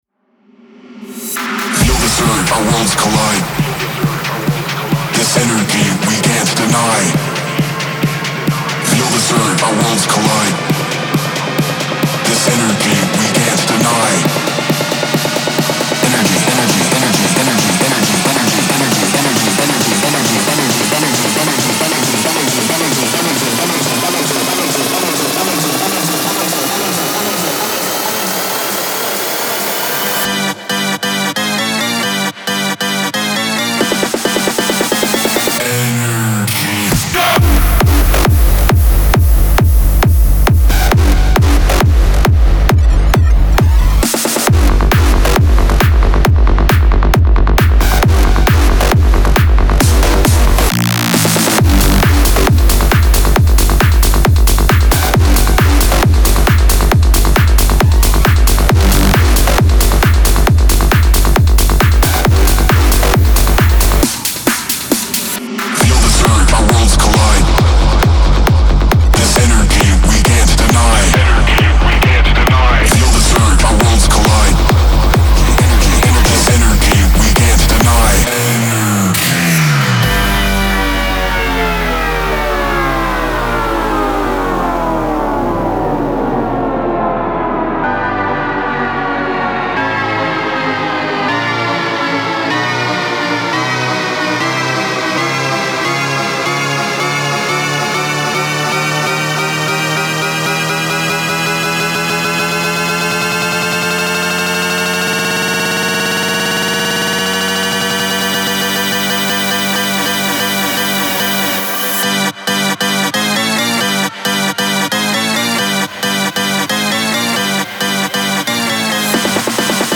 • Жанр: EDM